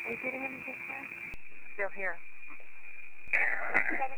These EVPs Mention Our Names
at Hills Cemetery, a place we both visit often.